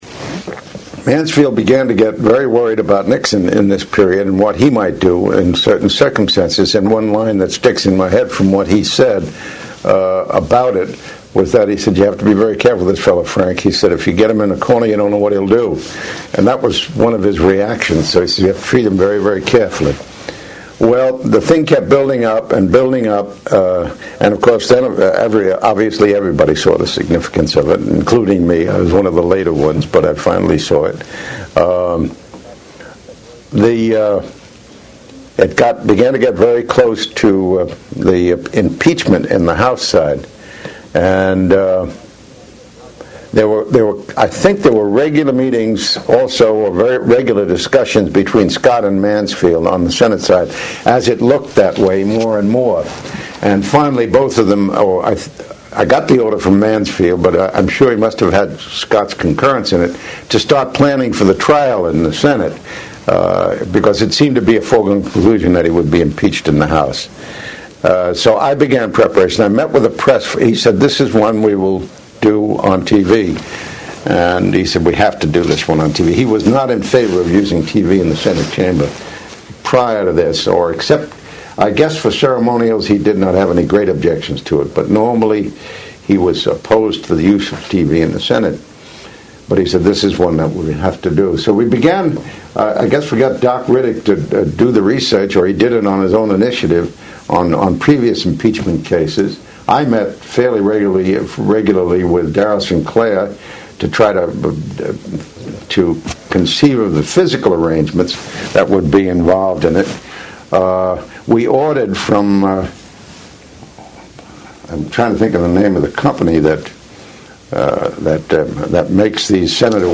Francis Valeo served as Secretary of the Senate from 1966-1977. In an excerpt from an oral history interview he gave in 1985, Valeo describes the Senate’s preparations for the impeachment trial of President Richard Nixon.